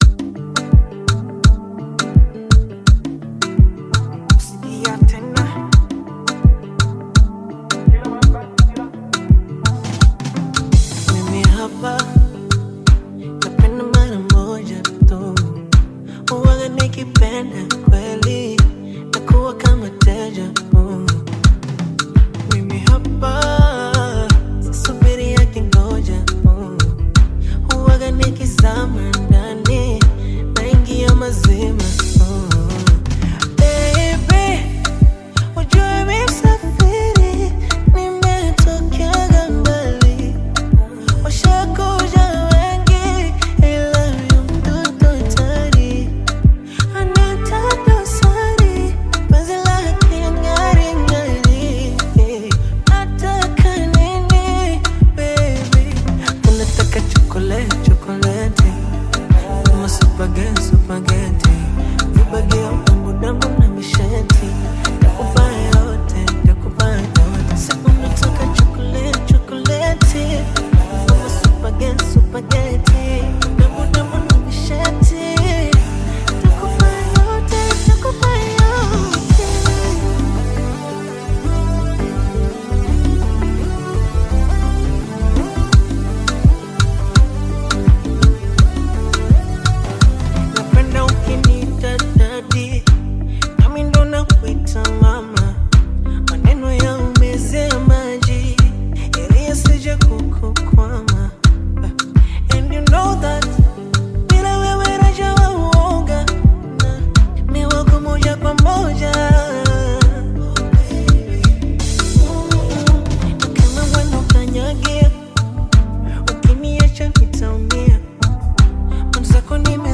Tanzanian Bongo Flava
Bongo Flava